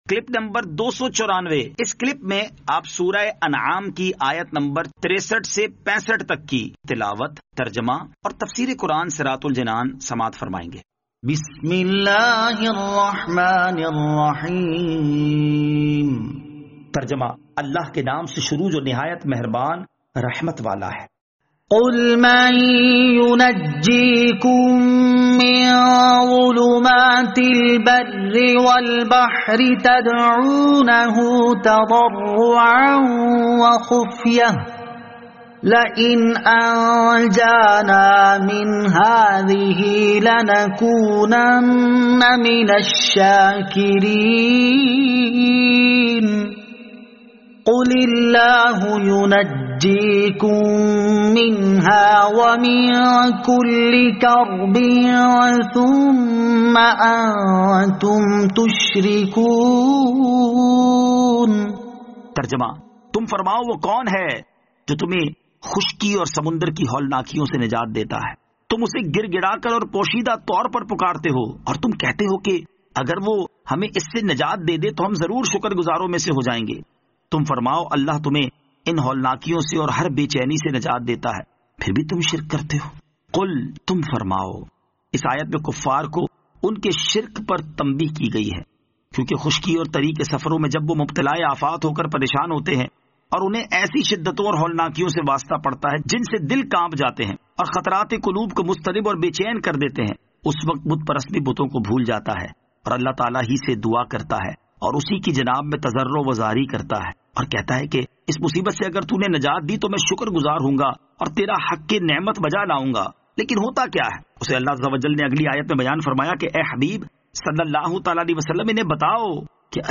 Surah Al-Anaam Ayat 63 To 65 Tilawat , Tarjama , Tafseer